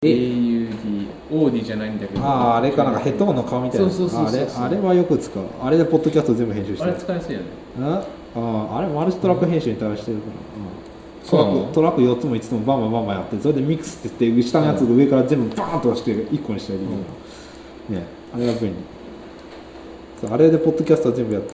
今日は部室で
感度が良すぎると周りの音をかなり録ってしまうってことだけは言える、うん。